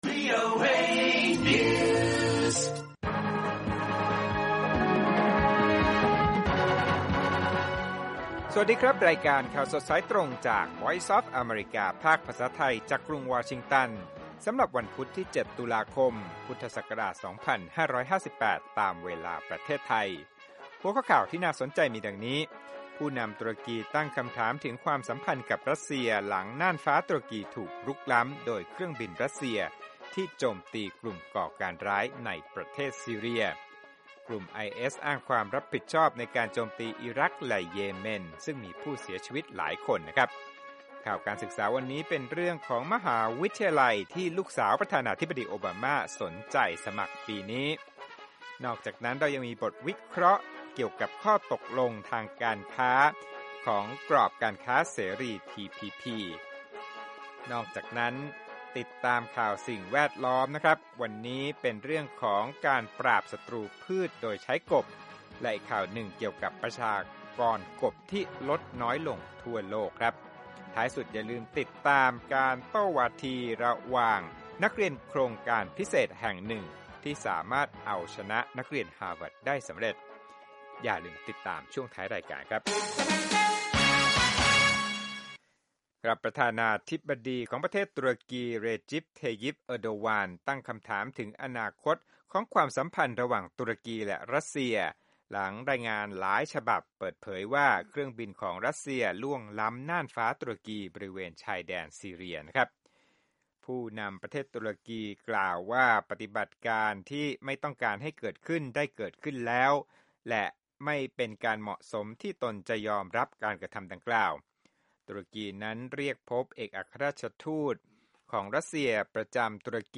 ข่าวสดสายตรงจากวีโอเอ ภาคภาษาไทย 6:30 – 7:00 น. วันพุธ 7 ต.ค. 2558